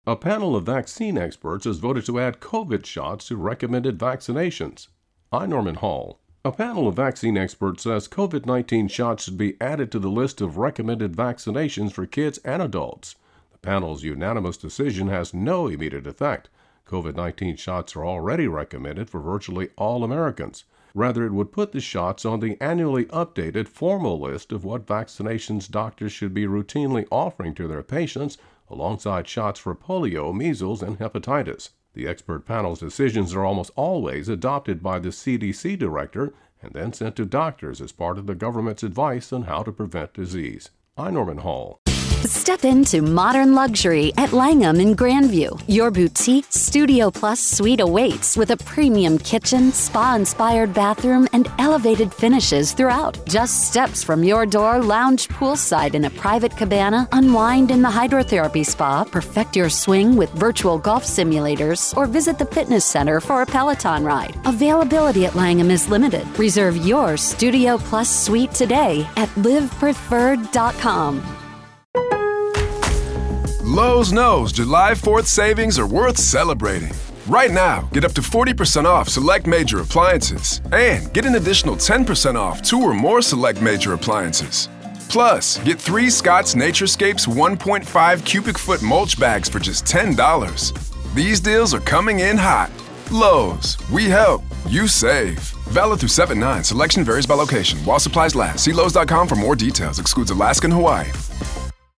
AP correspondent
reports